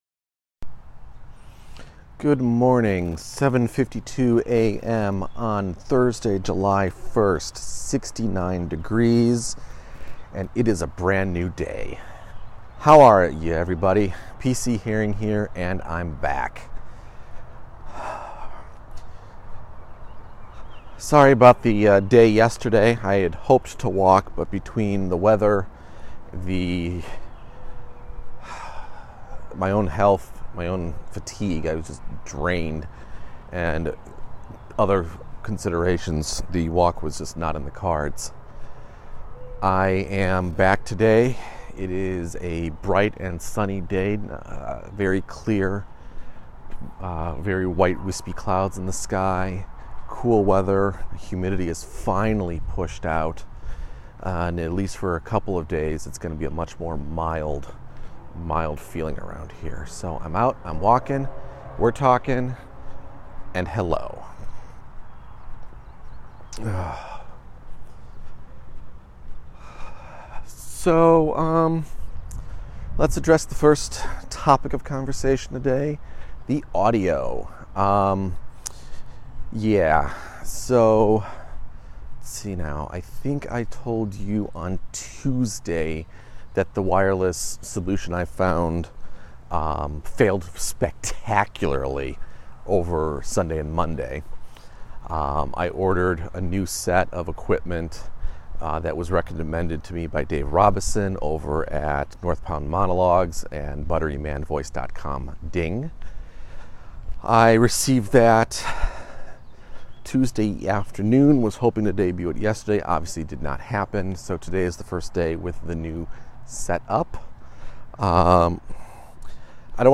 Back to the walk and talk today. Today I discuss the break in the weather, audio, the need to find a better writing routine, and then random solicitations left in peoples driveways.